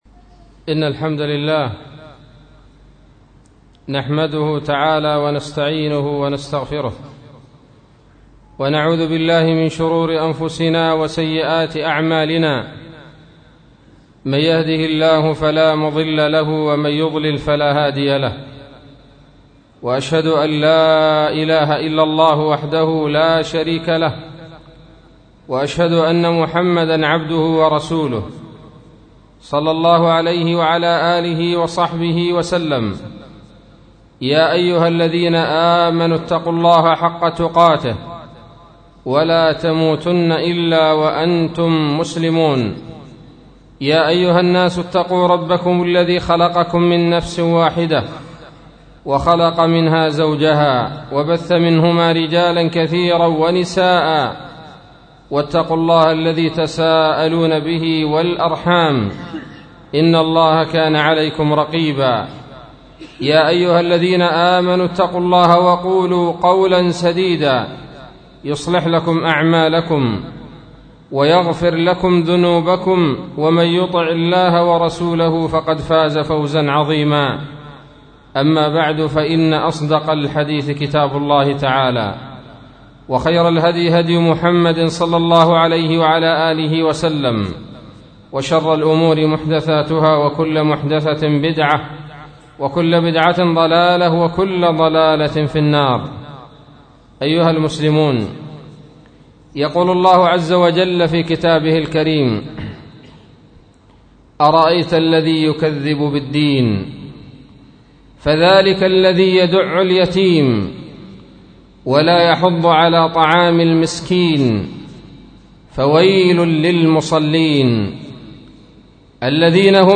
خطبة بعنوان : ((تفسير سورة الماعون)) 10 جمادي الاول 1437 هـ